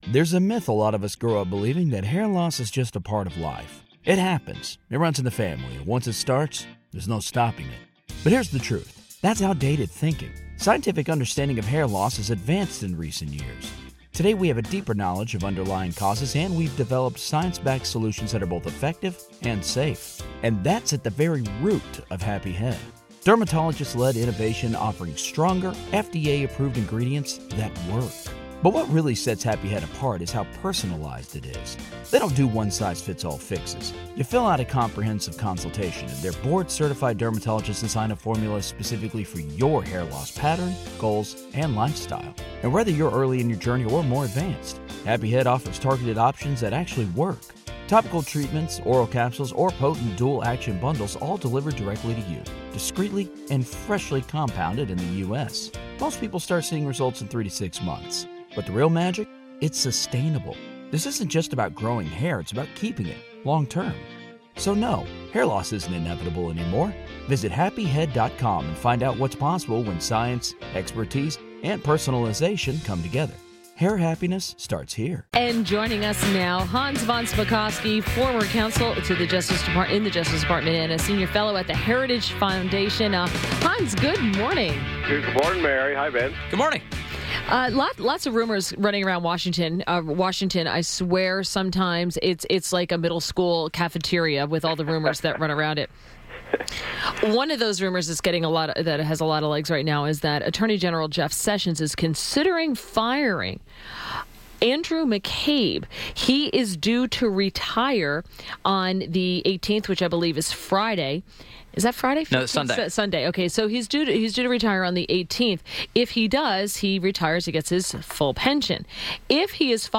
WMAL Interview - HANS VON SPAKOVSKY - 03.15.18